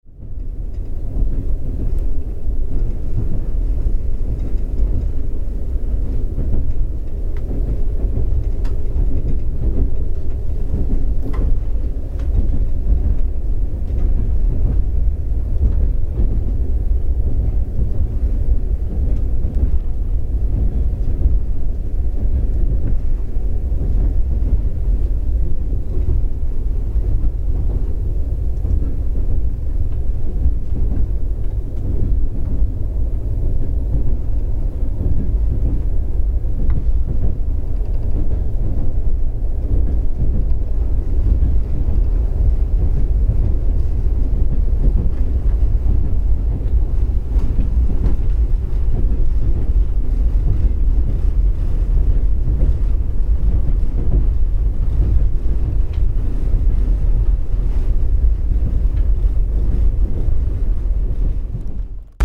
The motion of a sleeper train moving through open countryside recorded from a vestibule onboard.